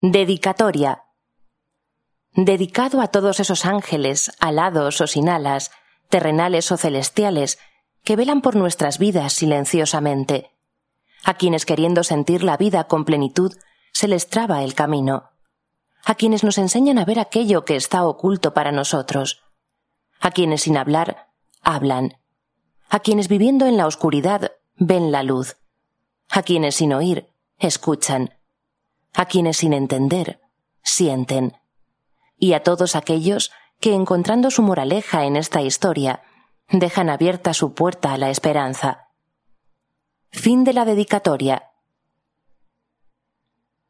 Audiolibro realizado por la Fundación ONCE